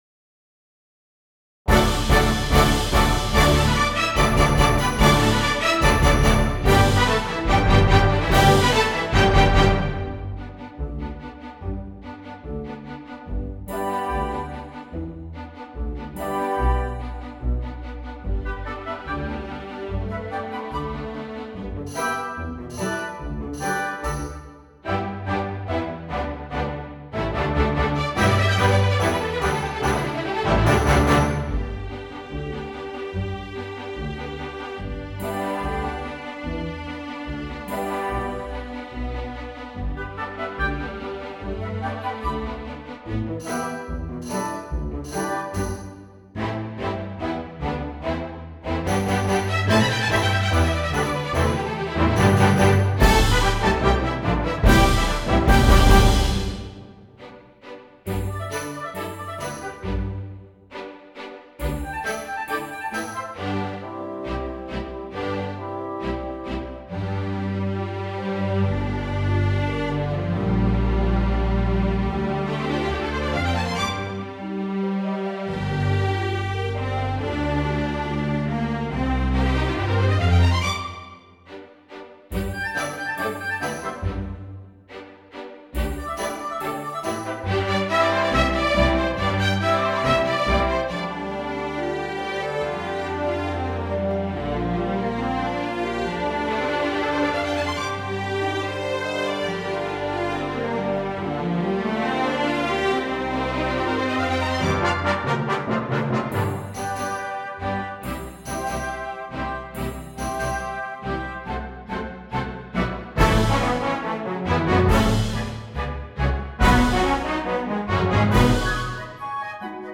二胡与乐队